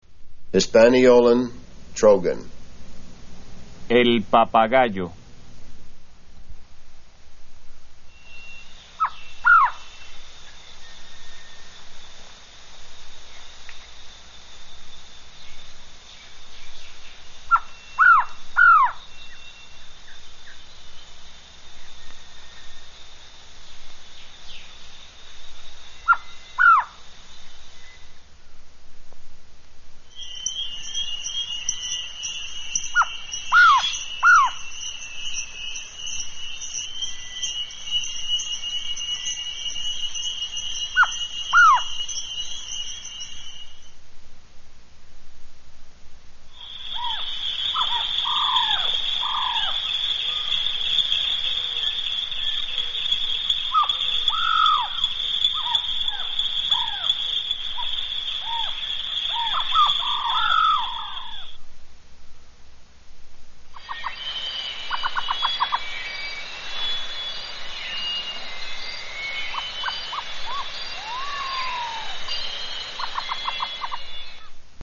Bird Sounds from Hispaniola
Hisp-Trogon-2.mp3